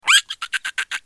دانلود صدای میمون 6 از ساعد نیوز با لینک مستقیم و کیفیت بالا
جلوه های صوتی